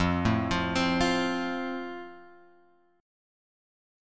F chord {x 8 7 5 6 5} chord